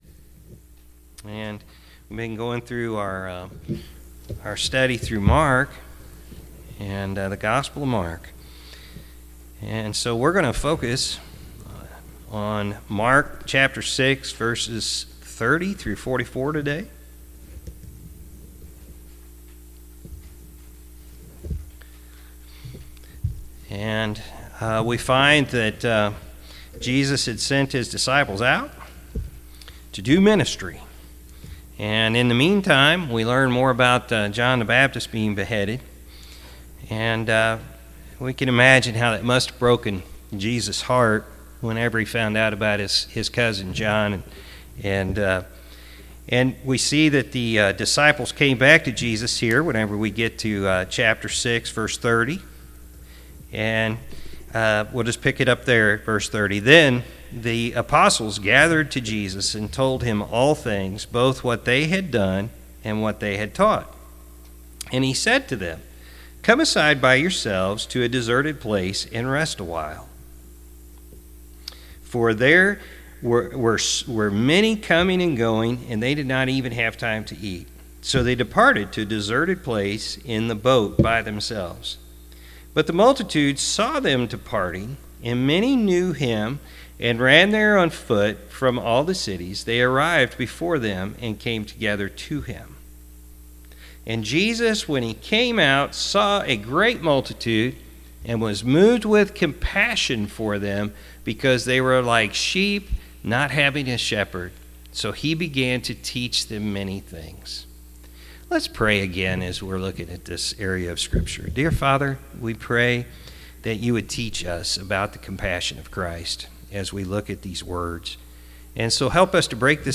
Oct-4-2020-morning-service.mp3